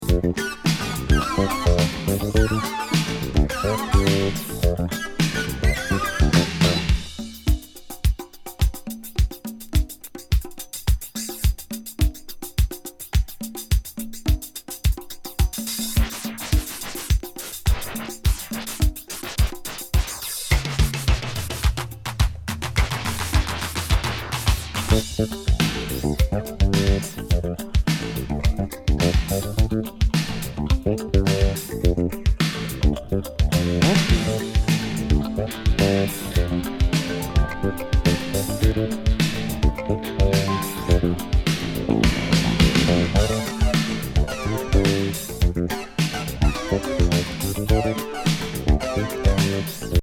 変シンセ入りインスト・エレクトロ・ファンク